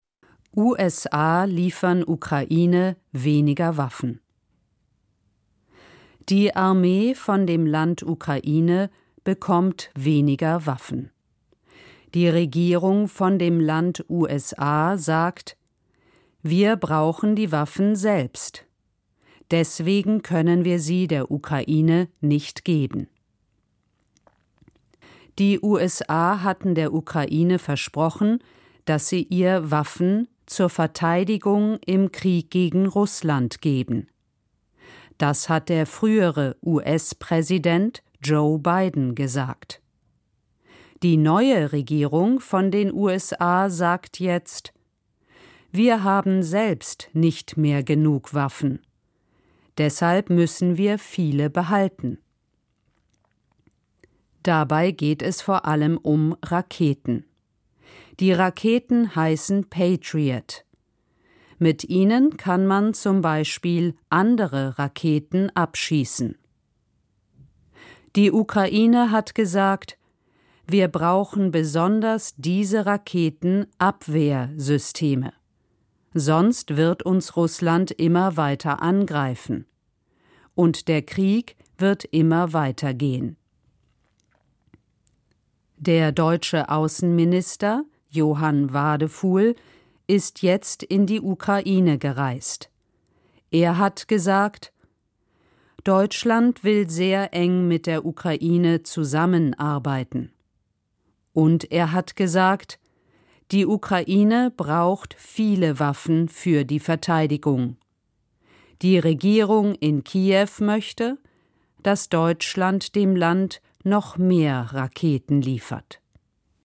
"nachrichtenleicht" - Nachrichten vom Deutschlandfunk in Einfacher Sprache